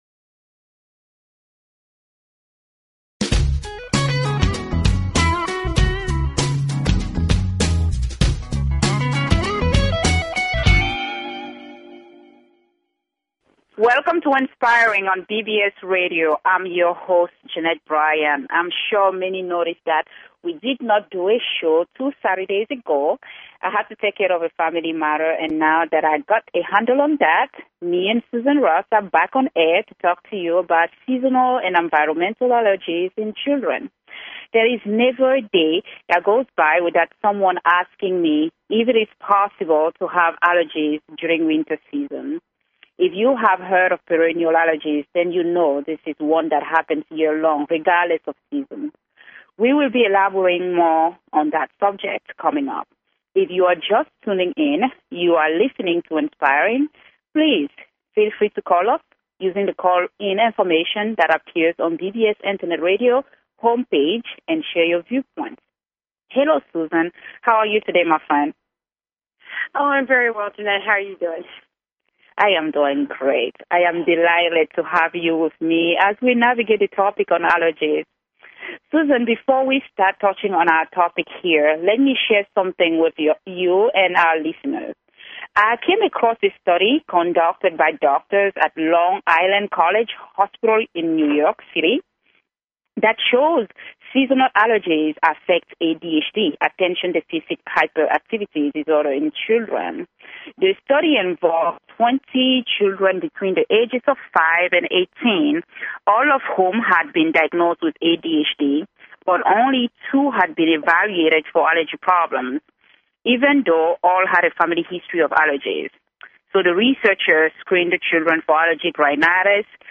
Talk Show Episode, Audio Podcast, Inspiring and Courtesy of BBS Radio on , show guests , about , categorized as